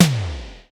TOM XC.TOM08.wav